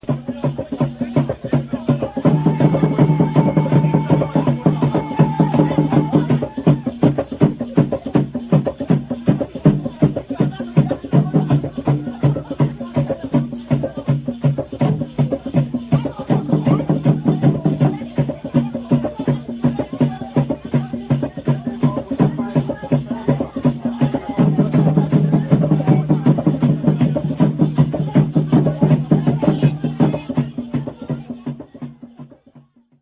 Batería de tambores
cónicos de una membrana
Batería de tres tambores, cuerpos de madera de cedro, en forma de conos truncados divergentes.
Tambor Dein-Dein: se golpea con un palillo. Estos dos mantienen la base o patrón rítmico del conjunto.
Característica: Toque propio de ceremonias públicas y/o religiosas
Procedencia, año: Gaan Seeí, Rio Suriname, Dtto. Brokopondo, Suriname, 1979